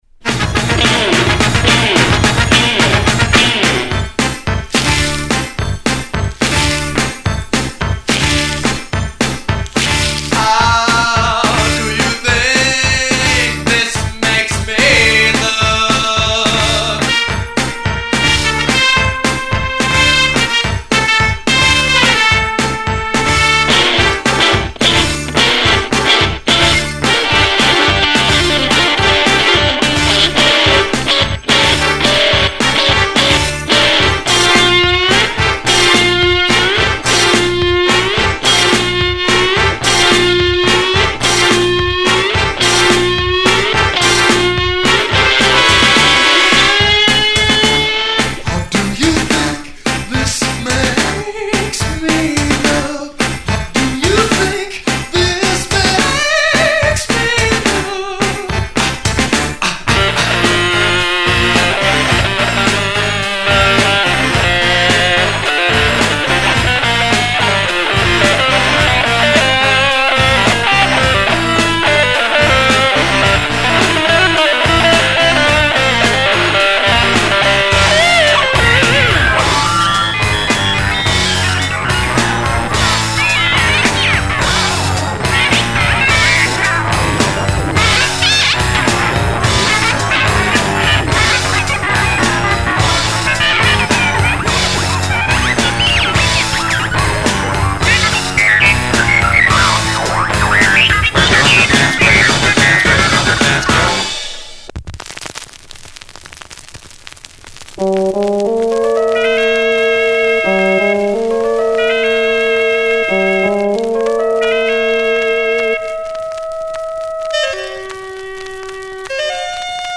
It’s actually three pieces strung together.
I sang and played all the instruments.
Interesting juxtaposition of styles and melodies